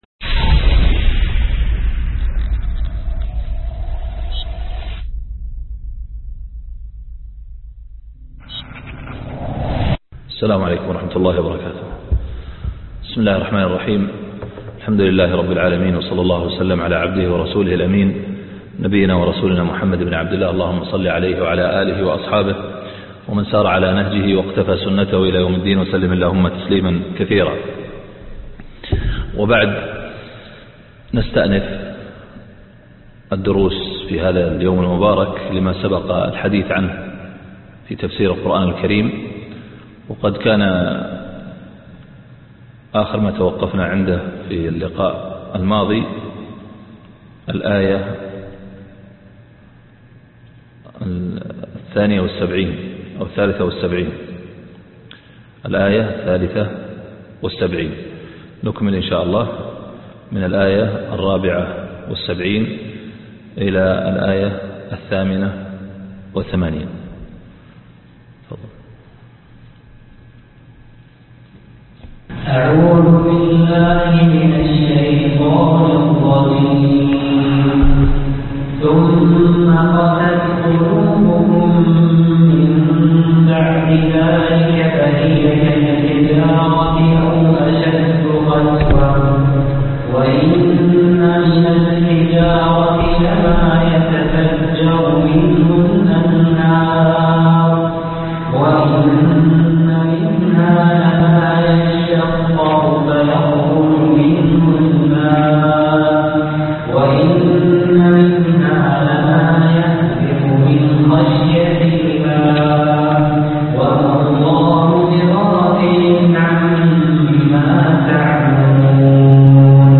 الدرس 7 تفسير سورة البقرة من الاية 74 الى الاية 88